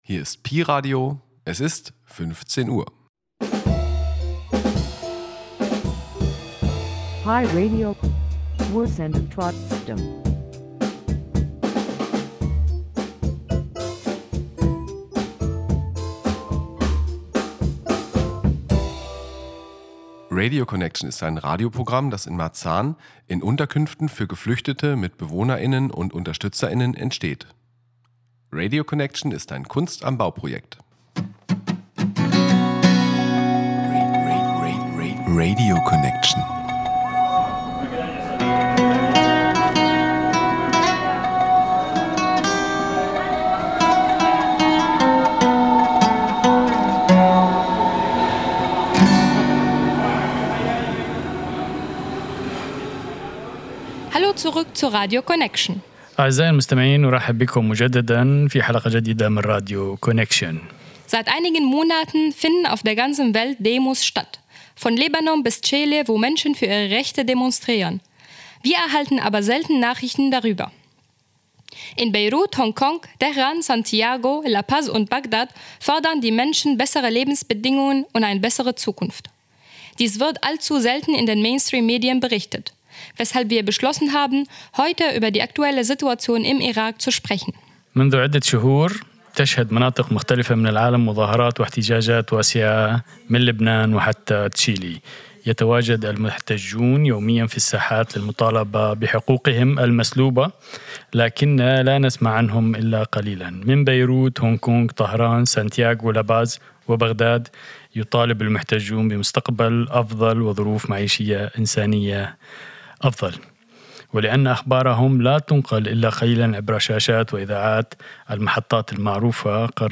Stimmen vom Tahrir Platz in Bagdad, Interview mit einem Demonstranten aus Bagdad, Gespräch mit einem Aktivisten über die Menschenrechtssituation im Irak